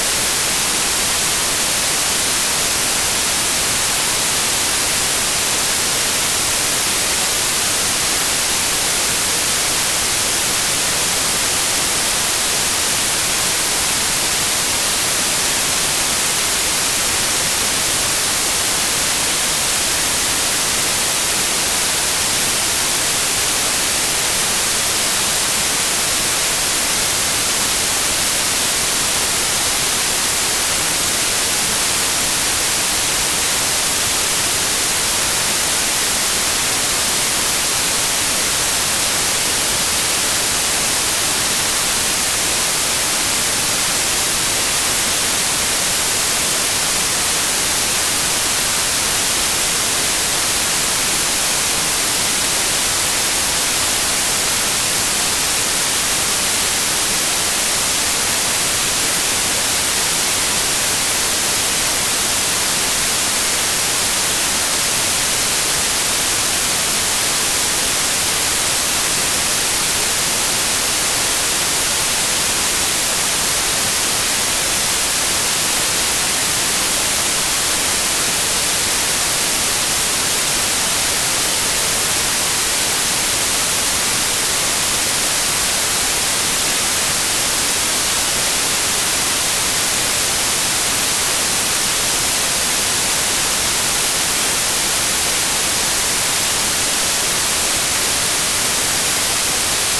White Noise
white.ogg